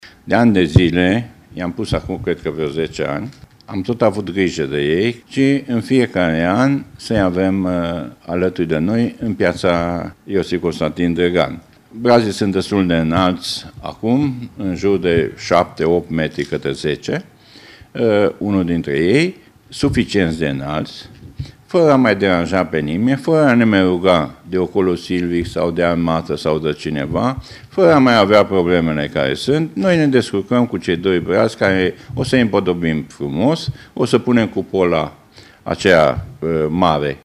Municipalitatea a decis, în schimb, că vor fi împodobiţi cei doi brazi plantaţi în Piaţa Drăgan, spune primarul Francisc Boldea.